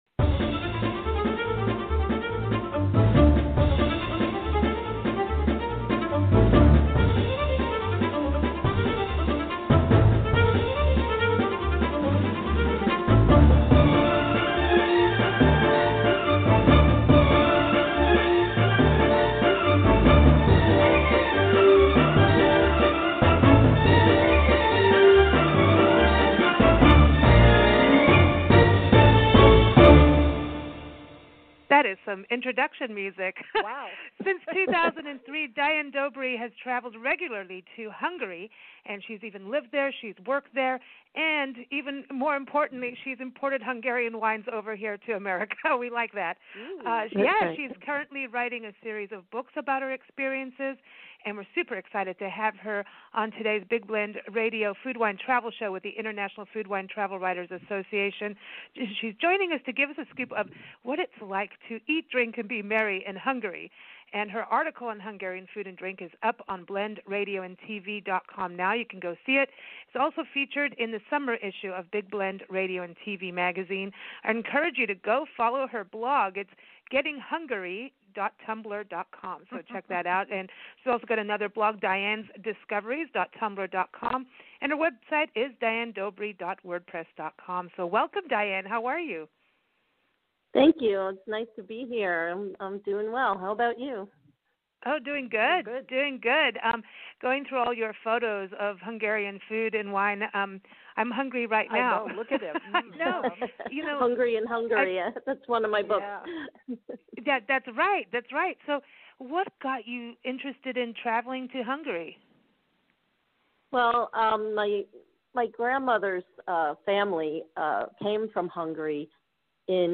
A Taste Of Hungary Interview